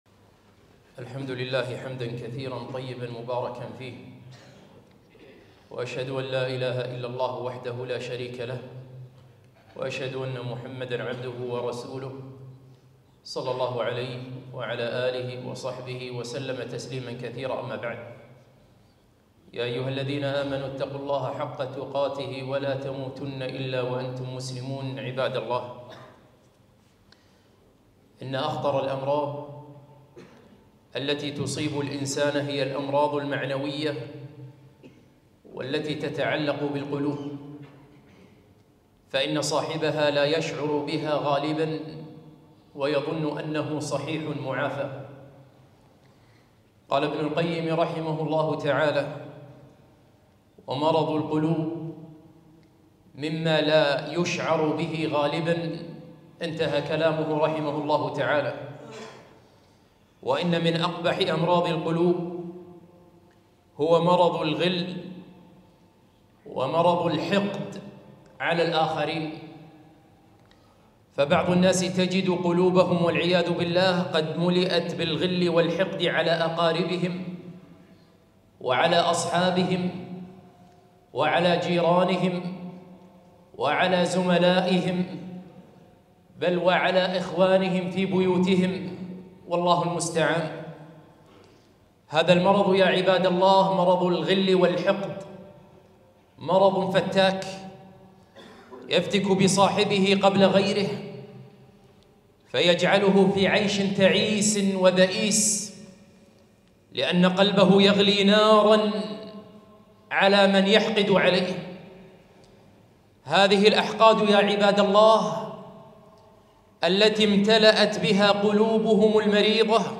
خطبة - البعد عن الغل والحقد